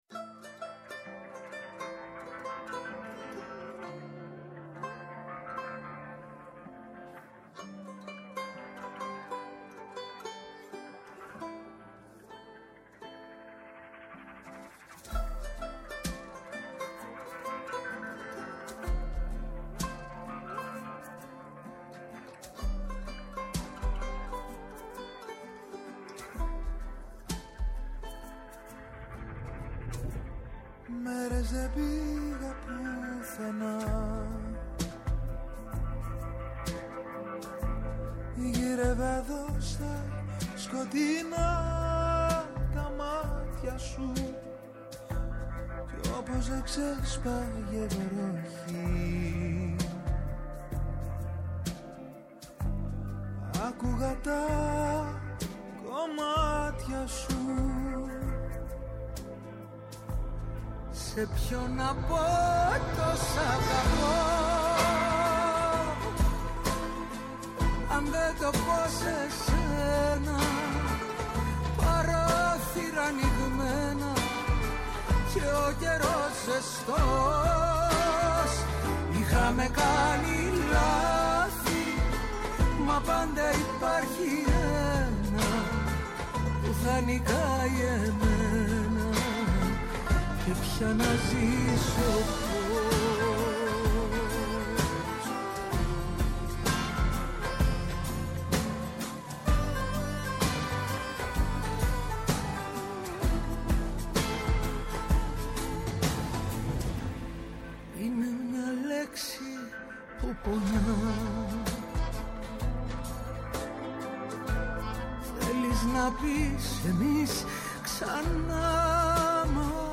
Σήμερα καλεσμένοι τηλεφωνικά